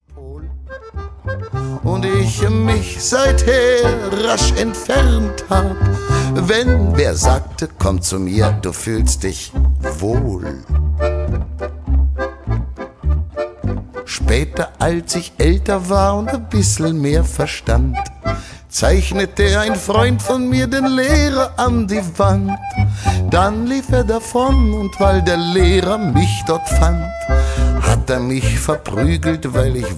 World Music From Berlin